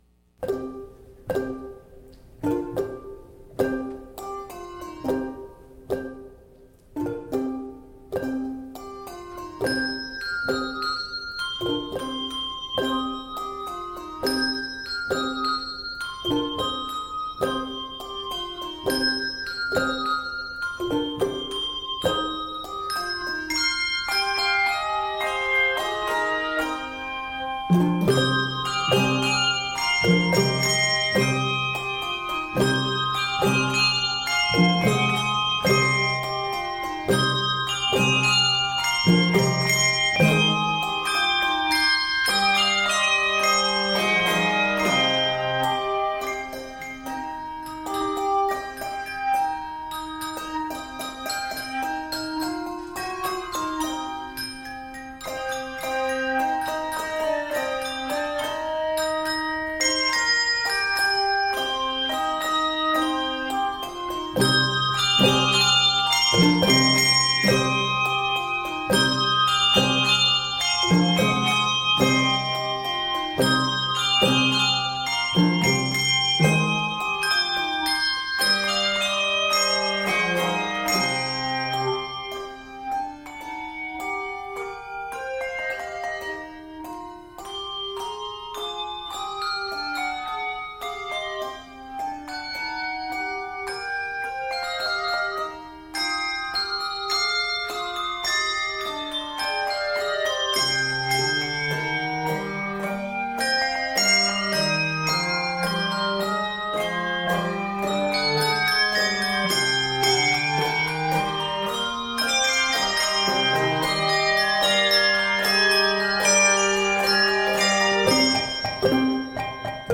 Keys of c minor and d minor.
Octaves: 3-5